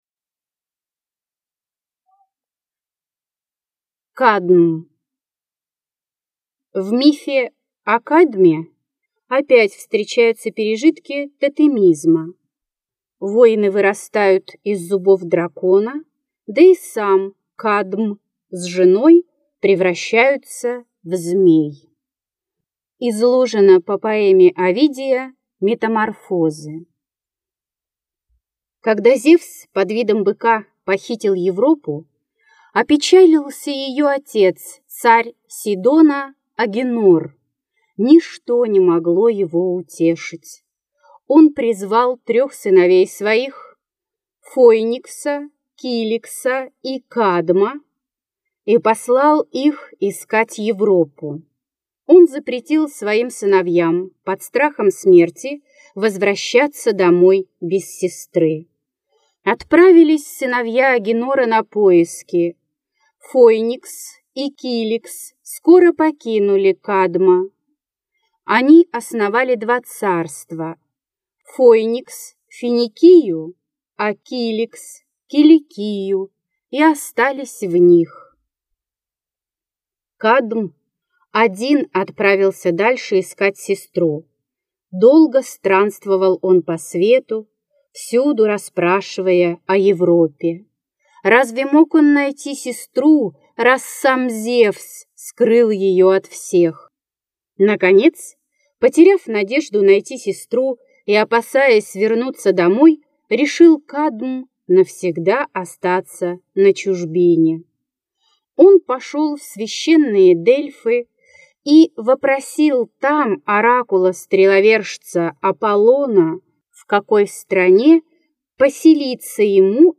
Аудиокнига Легенды и мифы древней Греции. Герои | Библиотека аудиокниг